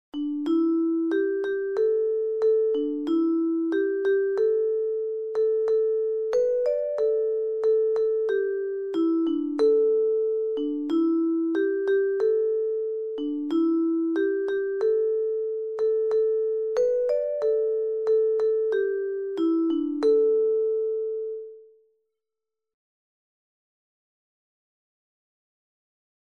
Allen zingen daarna: